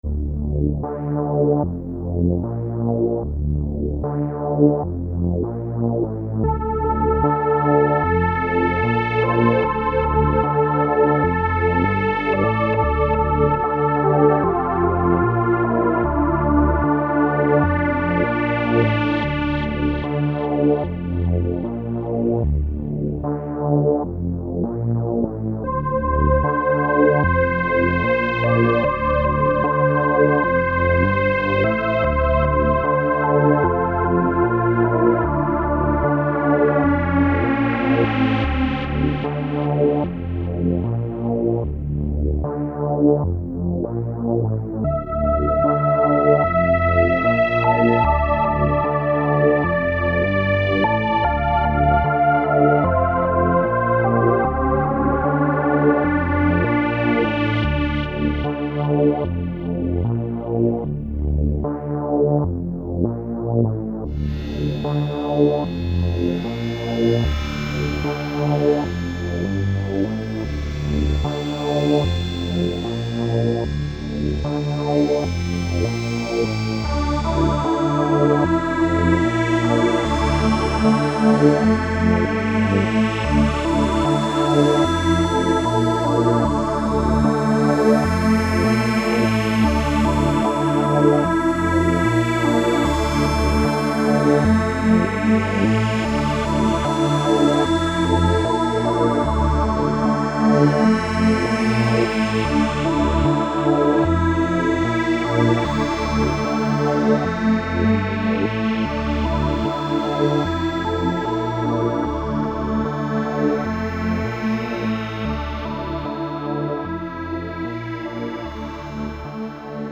Elektron Monomachine SFX60 synthesizer
auf Elektron Monomachine
Es sind keine externe Effekte benutzt worden. Die Audiodateien wurden nur normalisiert und teilweise mit einem Fade-Out bearbeitet.
– alle Klänge sind von der Super Wave Machine erzeugt (benutzte Untergruppen: SWave-Saw, SWave-Pulse, SWave-Ensemble)
– 3 Tracks wurden mit dem internen Sequenzer abgespielt
– wenige Parameter-Locks (Parameteränderungen je Step)